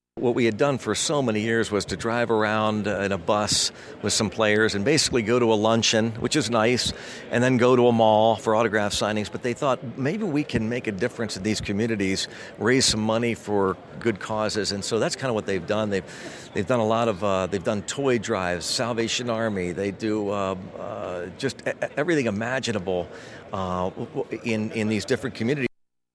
There was also a Q and A session hosted by the Voice of the Pirates Greg Brown, who talked about the evolution of the Care-A-Van from what it used to be to what it is today.
–Greg Brown, Pirates broadcaster